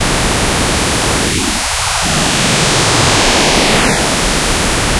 It was generated by an application called Coagula, which is a hybrid art program/sound generator. You can use it paint a pretty picture, and it can render that picture into this organic-sounding WAV file. (They sound phenomenal, since they tend to take full advantage of the separate left and right channels, creating some very neat stereo effects.)
staticdowntime.WAV